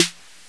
04.snare
snare.wav